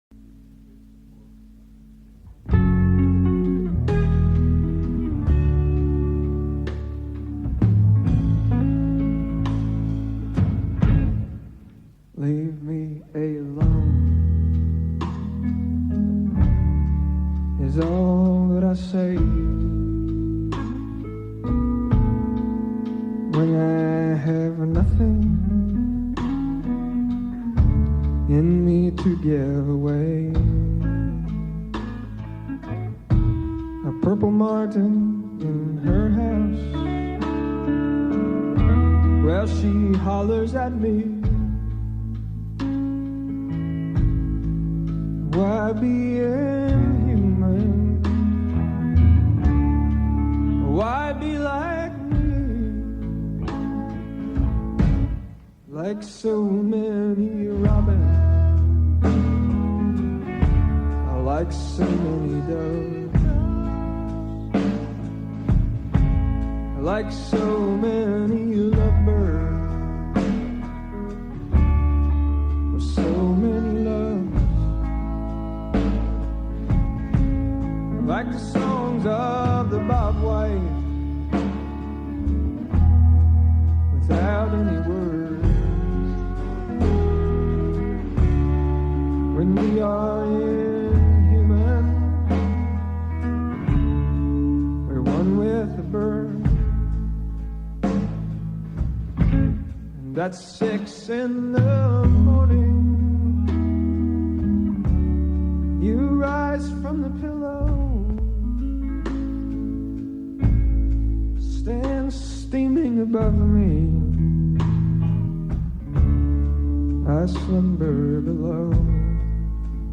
enregistrée le 26/01/1999  au Studio 105